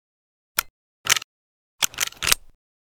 cz52_reload.ogg